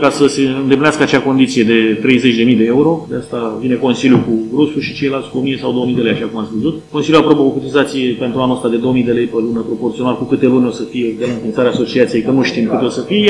Vineri, 27 iunie, Consiliul Județean Tulcea a votat înființarea Organizației de Management al Destinației Turistice „Eco‑Delta”.
Secretarul general al județului, Marius-Cristi Mihai, a oferit clarificări privind contribuția CJ Tulcea: